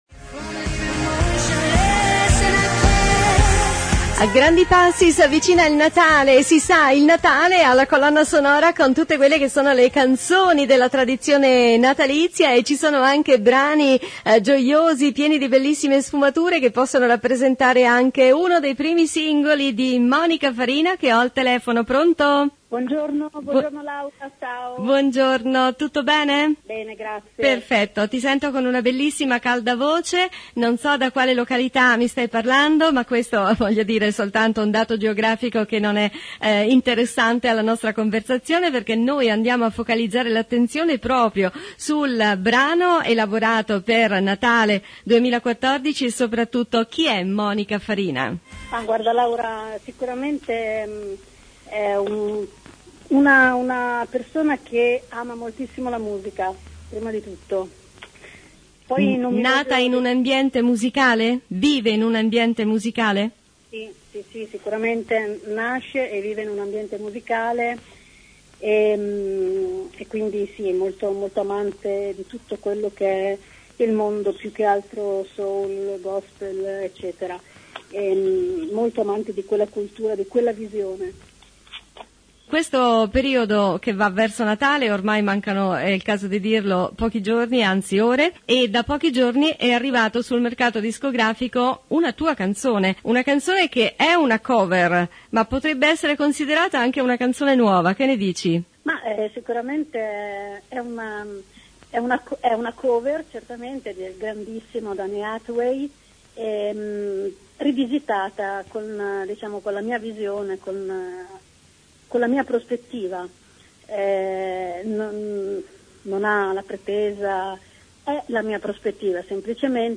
voce importante con il “vizio” del Soul e del Gospel
sapientemente reinterpretato
venature Jazz, un pizzico di Gospel ed un non nulla di R&B